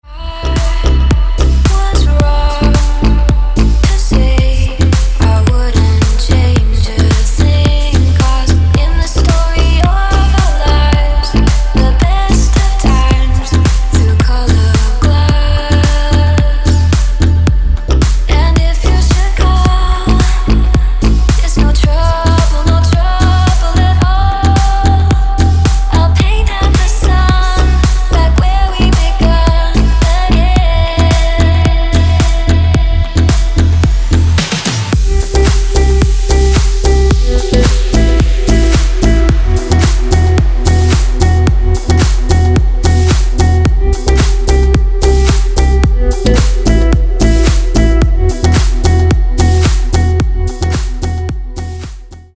• Качество: 160, Stereo
женский вокал
deep house
спокойные
Melodic
vocal